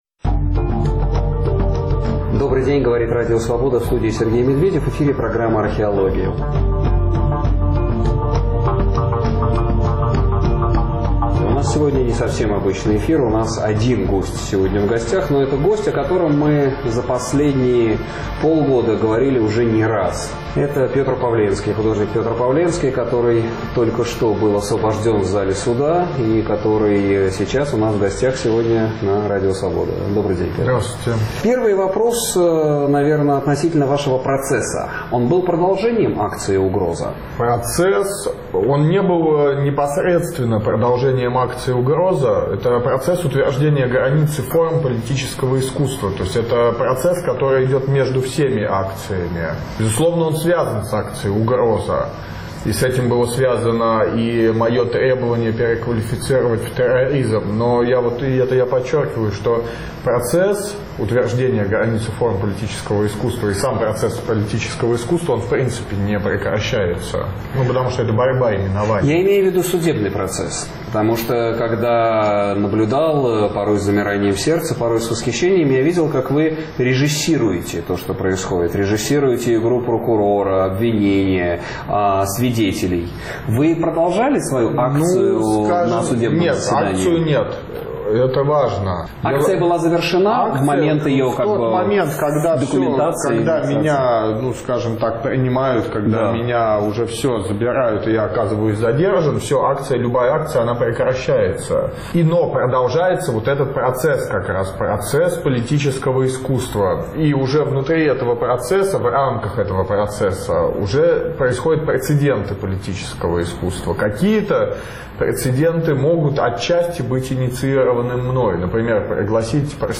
Разговор с Петром Павленским